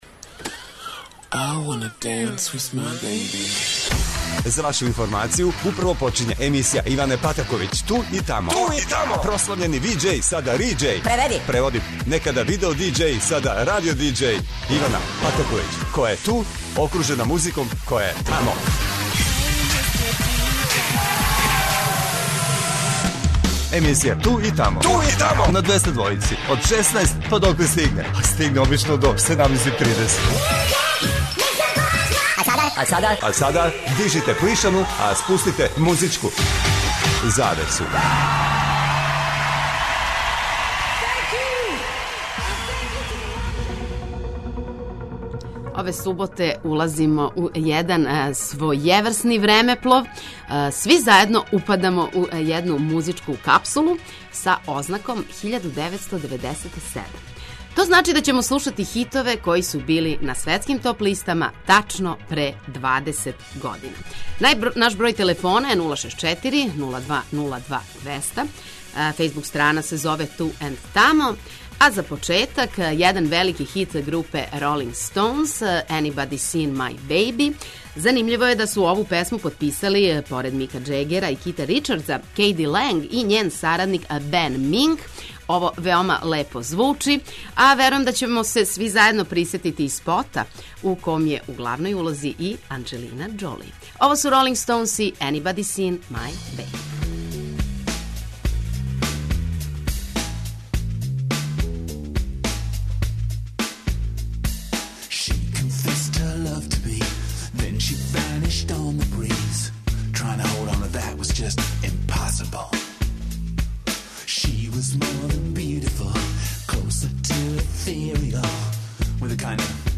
Данашња eмисија је својеврсни музички времеплов. На програму су стране поп-рок песме које су се слушале 1997. године.